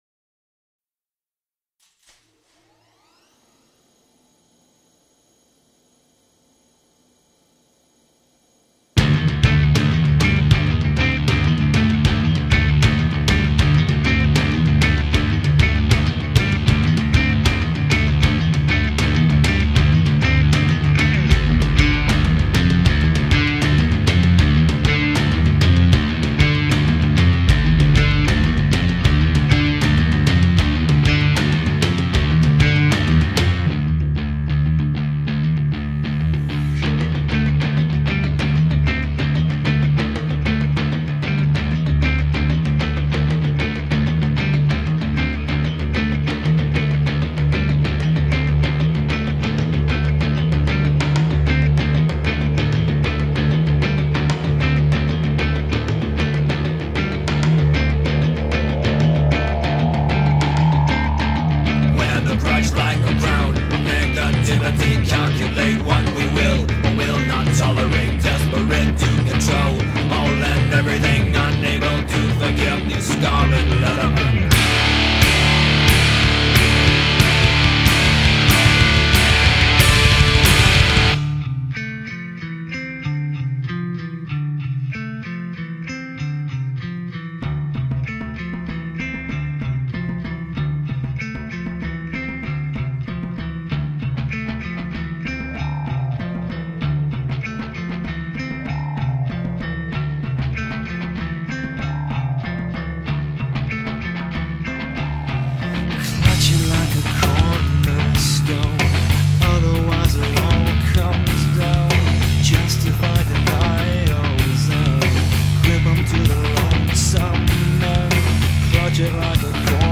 Progressive Metal, Heavy Metal, Alternative Rock